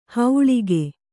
♪ hauḷige